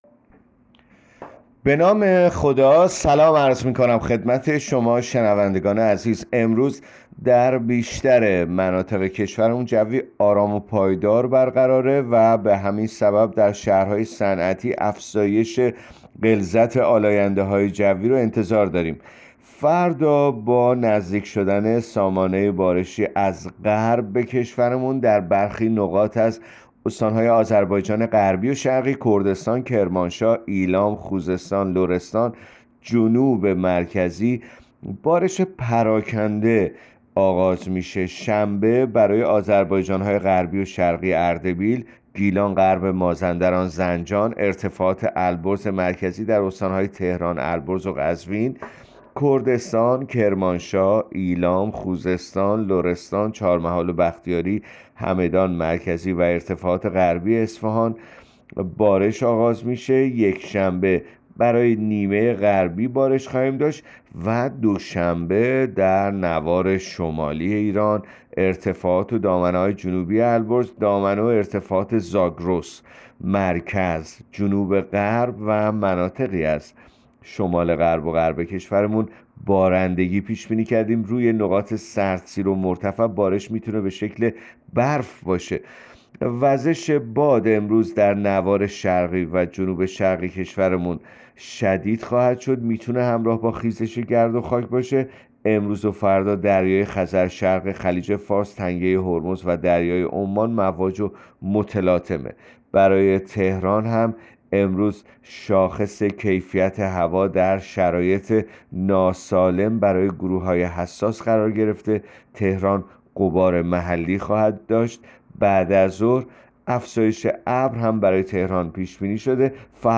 گزارش رادیو اینترنتی پایگاه‌ خبری از آخرین وضعیت آب‌وهوای ۴ بهمن؛